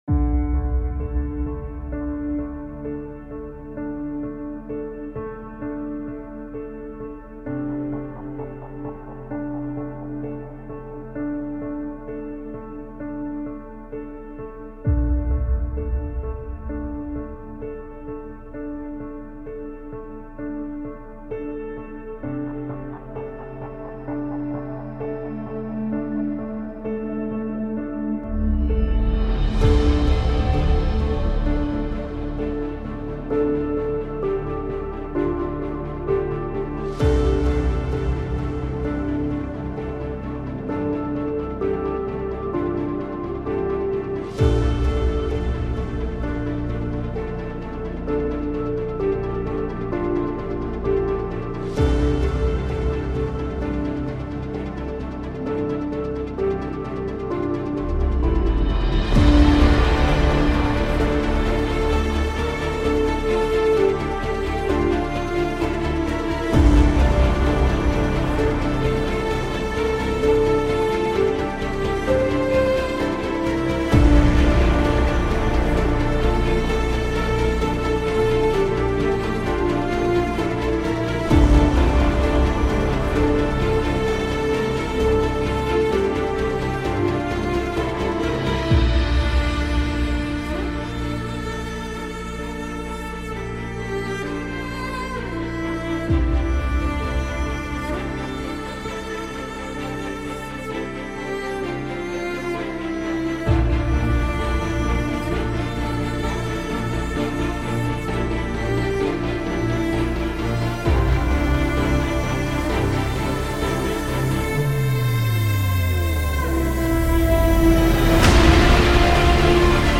موسیقی اینسترومنتال